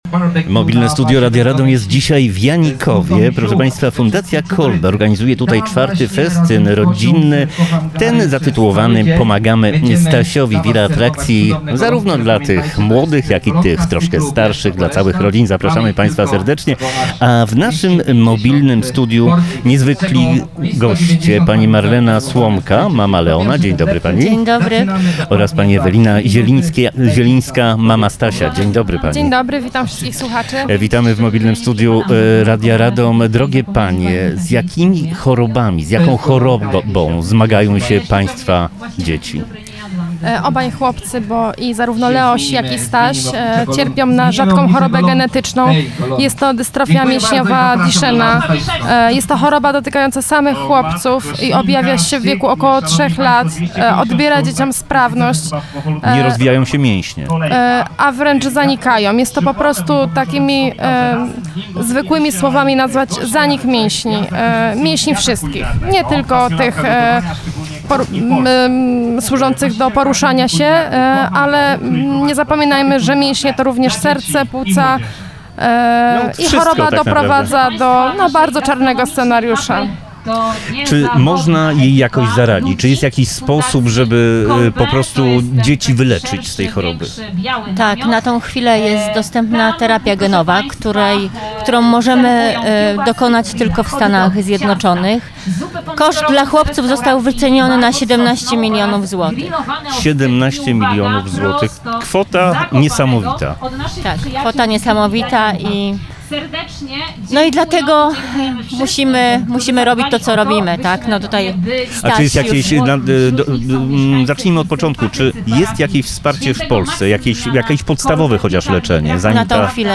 Mobilne Studio Radia Radom dzisiaj nadaje z Janikowa w gminie Kozienice.